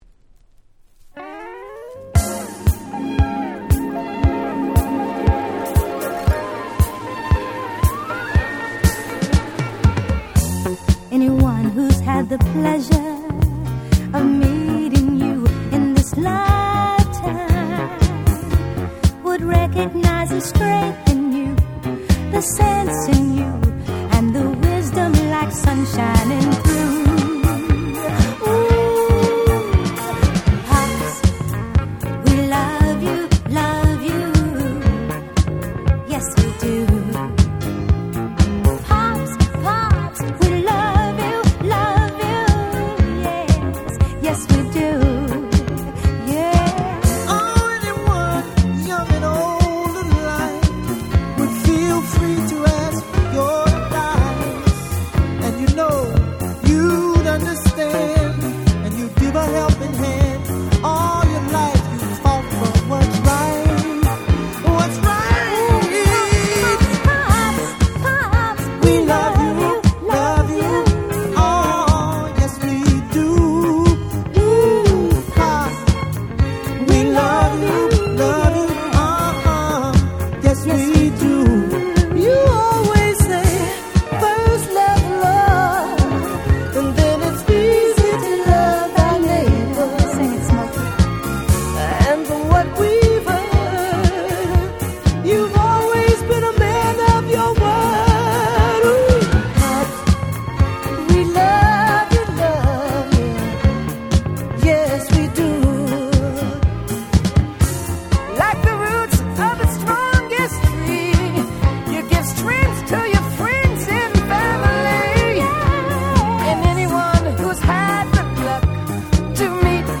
78' Super Nice Disco !!
多幸感溢れるハッピーなディスコナンバー！！